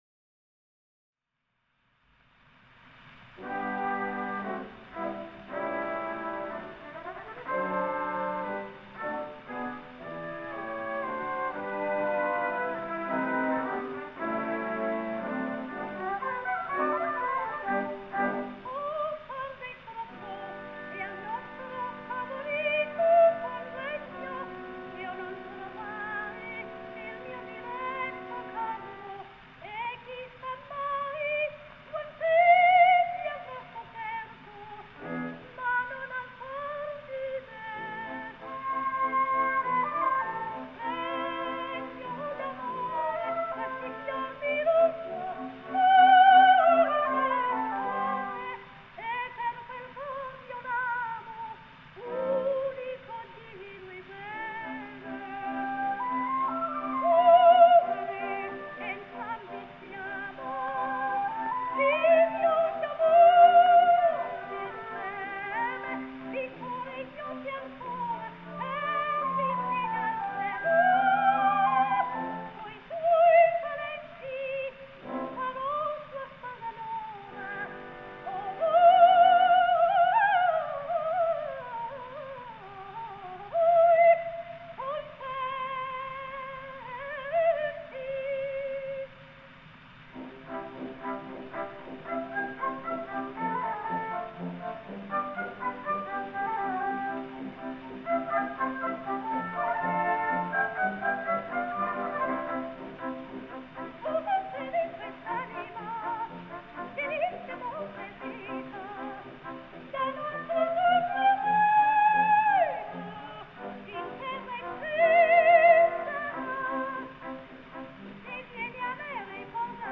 ТЕТРАЦЦИНИ (Tetrazzini) Луиза (29, по др. данным, 28 VI 1871, Флоренция - 28 IV 1940, Милан) - итал. певица (колоратурное сопрано).